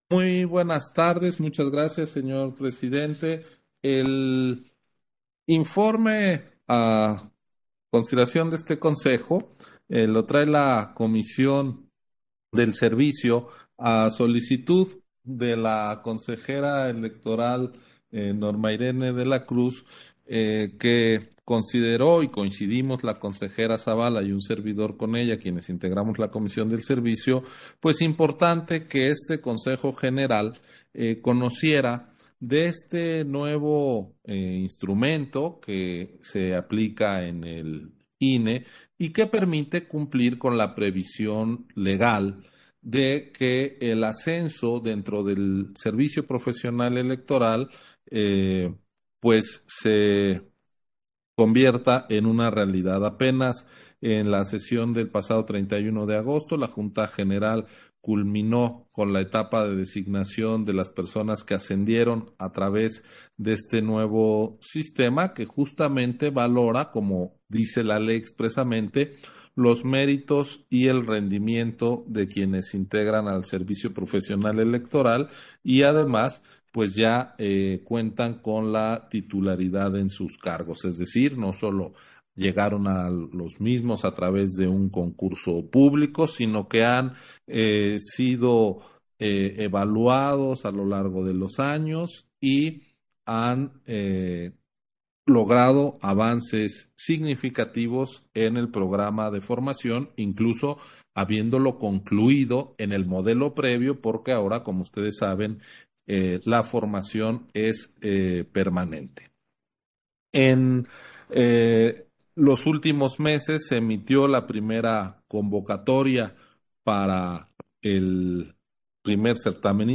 Intervención de Ciro Murayama, en el punto 1 de la Sesión Extraordinaria, relativo al informe sobre la conclusión y resultados finales de la invitación al segundo certamen de ascenso 2022